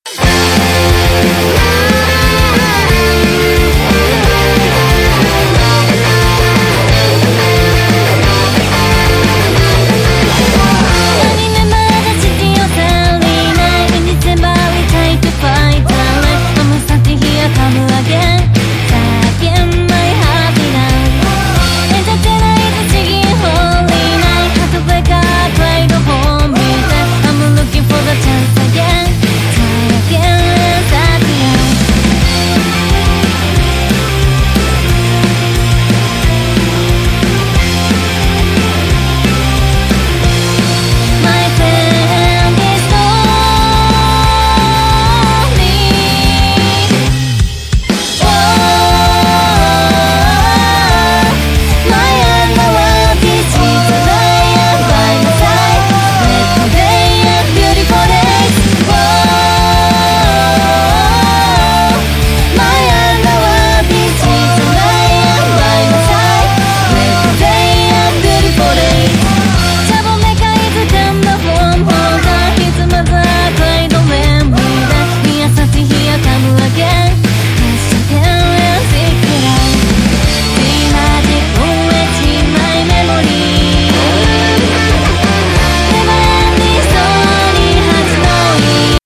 今作も言うまでもなく、POP感、疾走感、哀愁感の三拍子は言うことながら、誰の耳にでもしなやかに刺さるハイブリッドPOP。